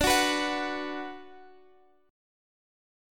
B5/D chord